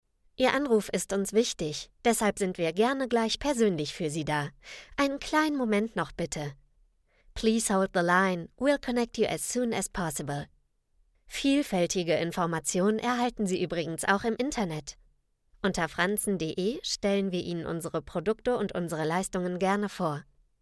Female
Bright, Character, Cheeky, Confident, Cool, Corporate, Friendly, Natural, Soft, Warm, Versatile, Young, Approachable, Assured, Authoritative, Bubbly, Conversational, Energetic, Engaging, Funny, Posh, Reassuring, Sarcastic, Smooth, Streetwise, Upbeat
My fresh and young, yet warm voice is often described as special and trustworthy at the same time.
Microphone: Neumann TLM49